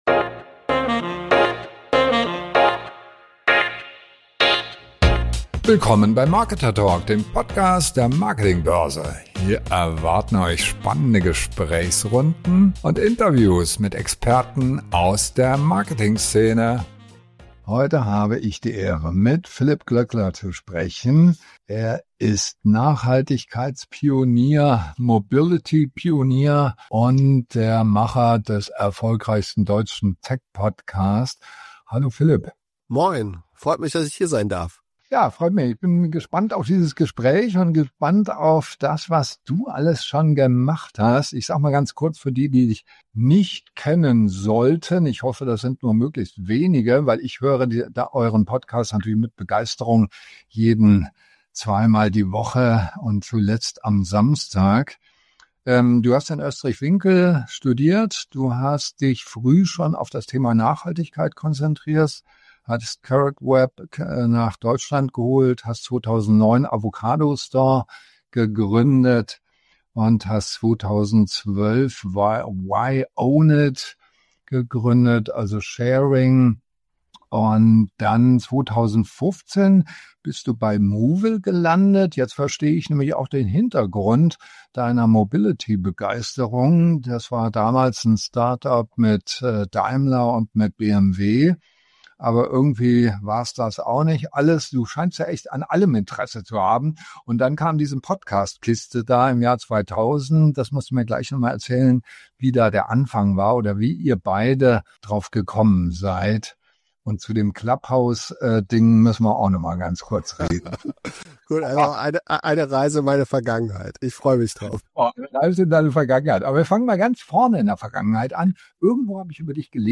Wie baut man als Creator die stärkste Vertrauensbindung zum Publikum auf? Im Gespräch mit einem Podcast-Pionier, der drei erfolgreich Unternehmen gegründet hat und jetzt mit seinem Partner eine der einflussreichsten Audio-Marken im deutschsprachigen Raum betreibt.